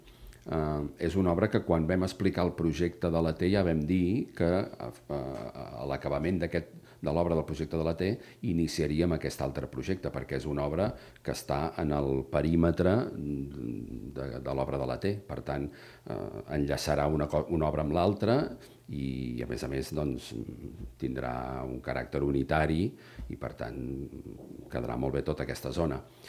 Segons ha explicat el regidor d'Urbanisme, Xavier Collet, s'espera poder licitar l'obra a finals d'any, un cop acabin els treballs de la zona coneguda com la “T”.